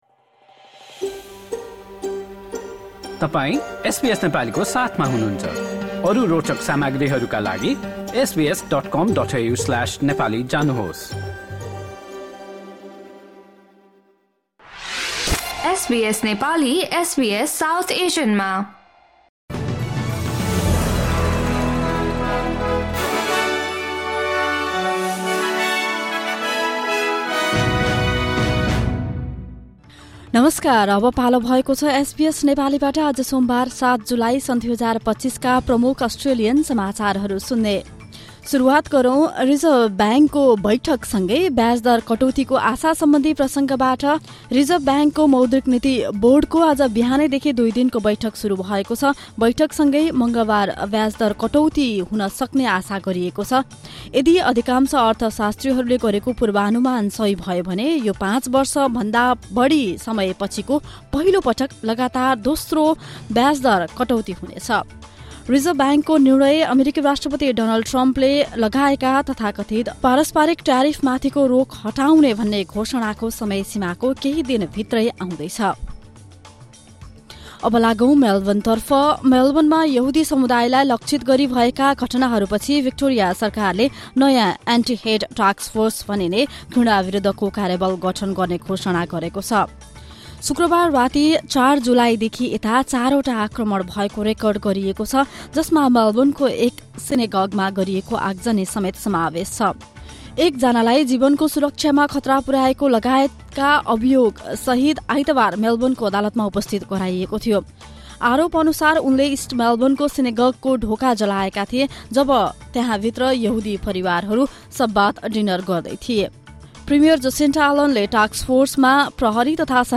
SBS Nepali Australian News Headlines: Monday, 7 July 2025